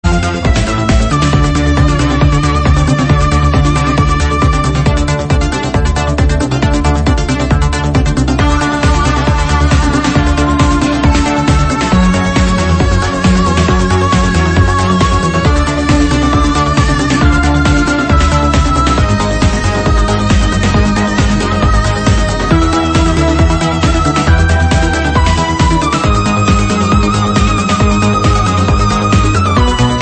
EDM remix